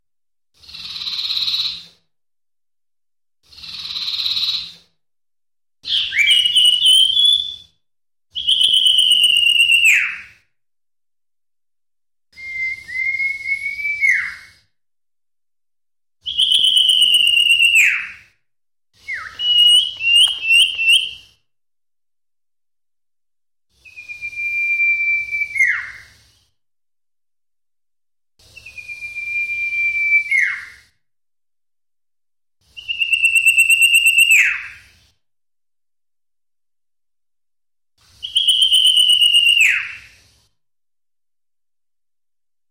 Burung ini terkenal akan suaranya yang unik, suara kicau sirtu gacor mampu menghipnotis para penikmat suara burung untuk memelihara burung kecil satu ini.
3. Suara Sirtu Masteran
Berikut ini suara sirtu jantan dewasa yang berkicau dengan sangat gacor,